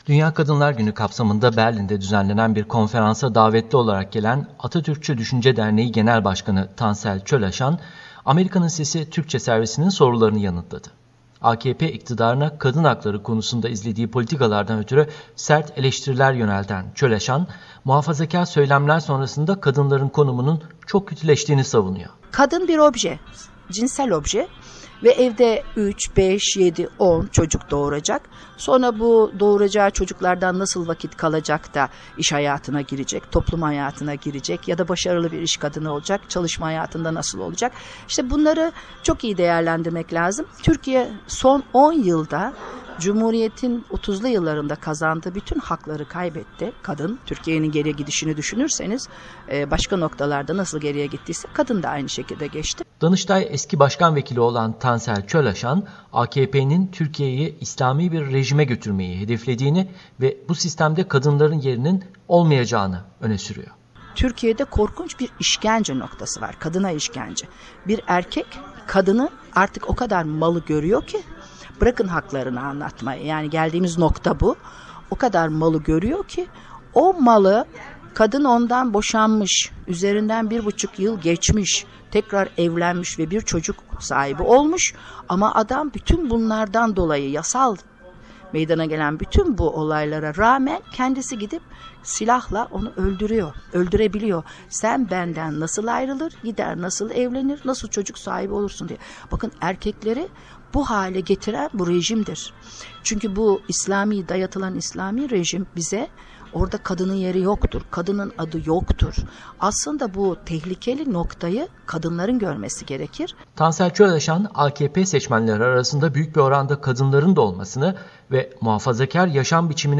Tansel Çölaşan'la söyleşi